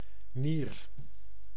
Ääntäminen
Ääntäminen France Tuntematon aksentti: IPA: /ʁɛ̃/ Haettu sana löytyi näillä lähdekielillä: ranska Käännös Konteksti Ääninäyte Substantiivit 1. nier anatomia Suku: m .